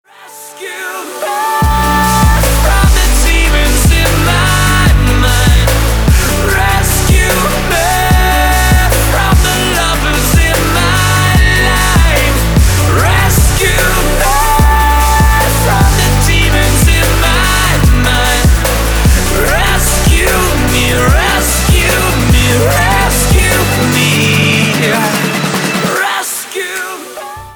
ROCK отрывки для звонка